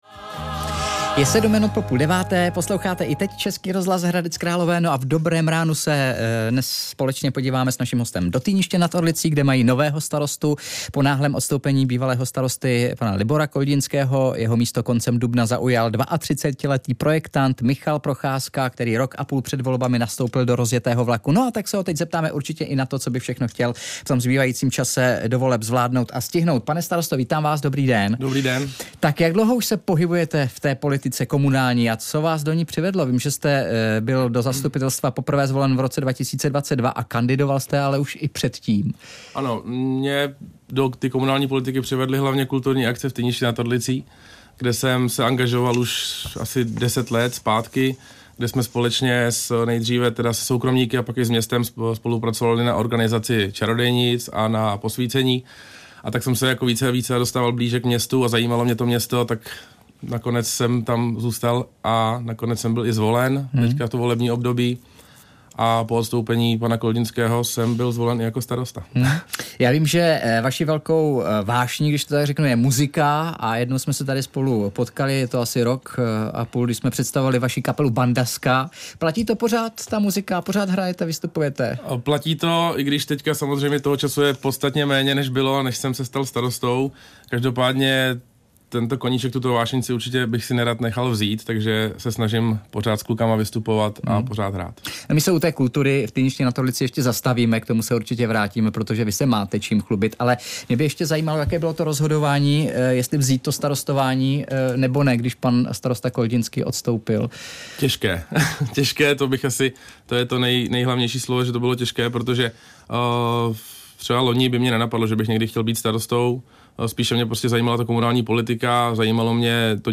Host ve studiu: Komunální politika je poslání, pracujme spolu, nikoliv proti sobě, říká starosta Týniště nad Orlicí - 18.06.2025